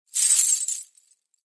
Danza árabe, bailarina mueve las pulseras al bailar 03
moneda
Sonidos: Acciones humanas